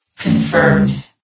CitadelStationBot df15bbe0f0 [MIRROR] New & Fixed AI VOX Sound Files ( #6003 ) ...
confirmed.ogg